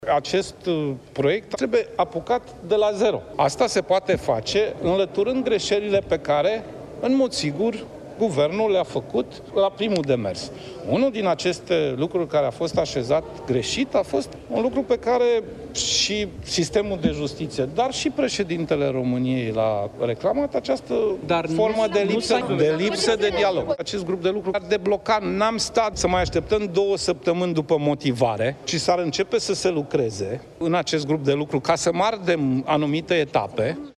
Președintele interimar al PSD, Sorin Grindeanu: „Acest proiect trebuie apucat de la zero”